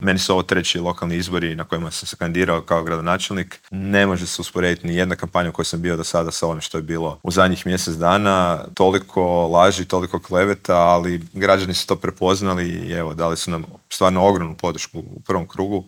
On je u prvom krugu lokalnih izbora osvojio 47,59 posto glasova Zagrepčana, a u Intervjuu tjedna Media servisa istaknuo je da je najvažnija većina u zagrebačkoj Gradskoj skupštini: